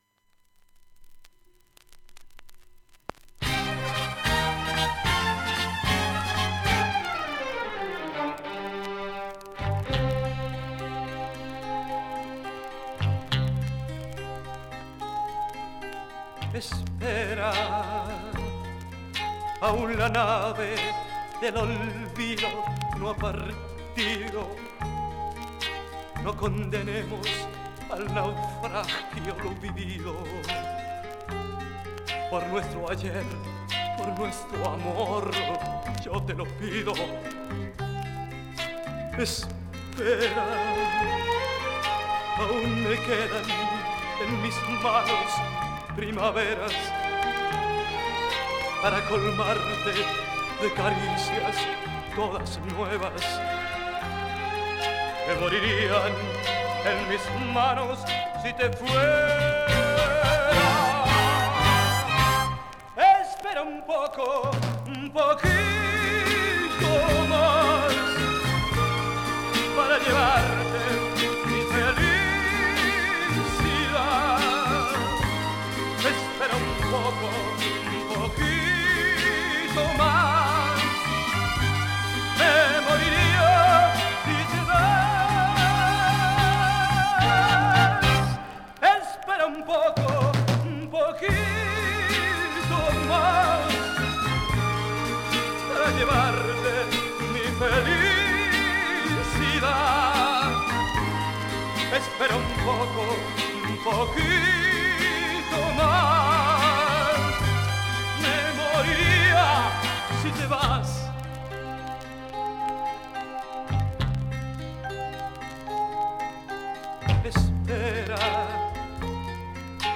スパニッシュ風マイナー歌謡